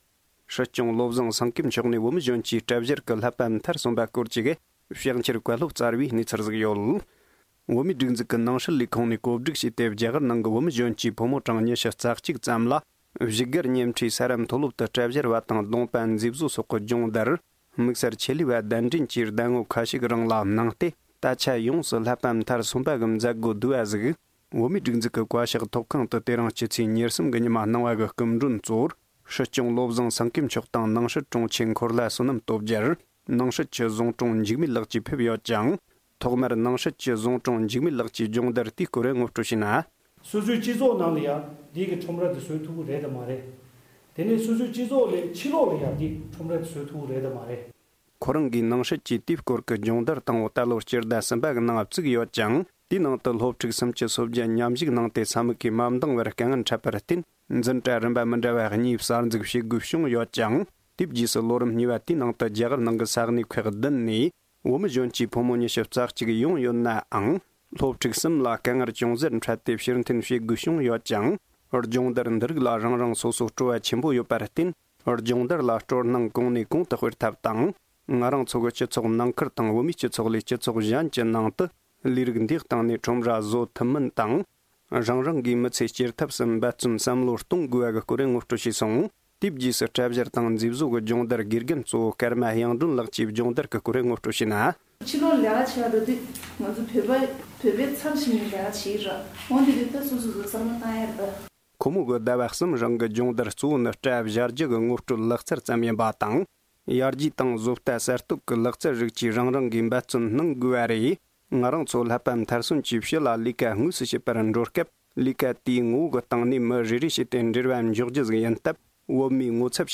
སྲིད་སྐྱོང་མཆོག་ནས་ཟླ་གསུམ་རིང་སྐྲ་མཛེས་བཟོའི་ཆེད་ལས་སྦྱོང་བརྡར་ནང་མཉམ་ཞུགས་བྱེད་མཁན་ཚོར་གསུང་བཤད།